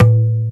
TABLA 16.WAV